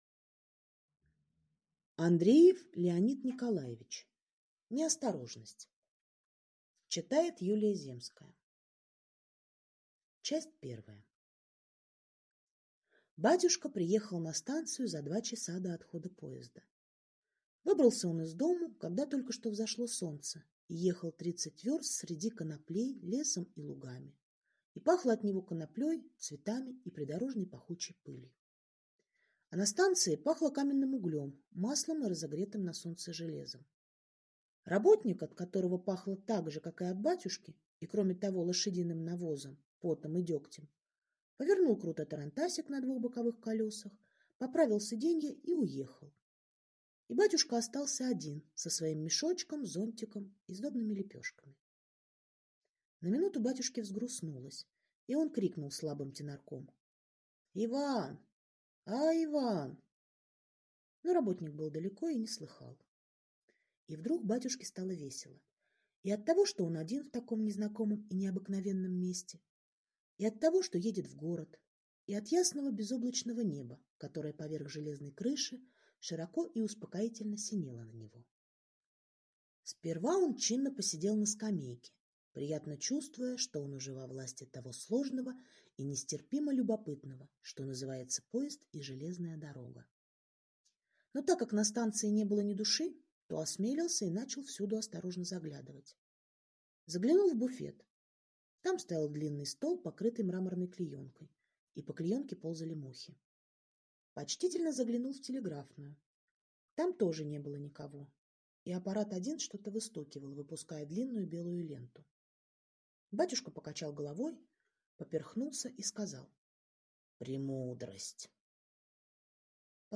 Аудиокнига Неосторожность | Библиотека аудиокниг